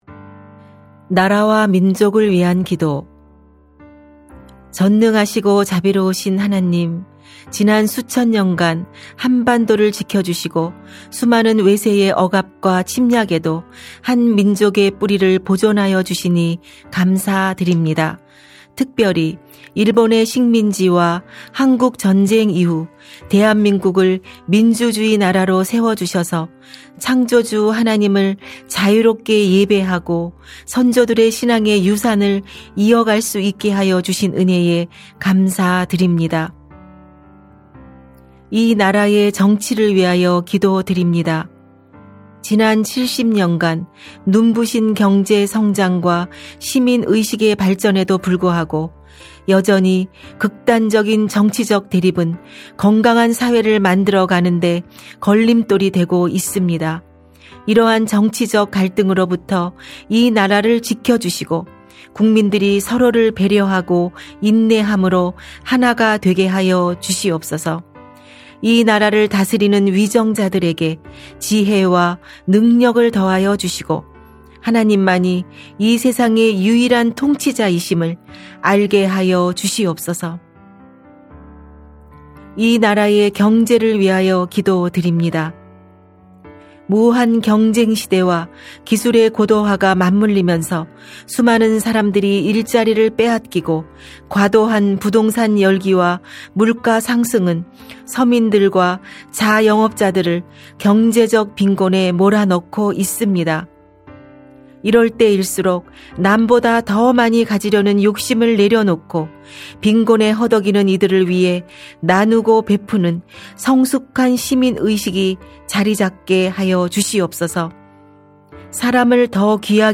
중보기도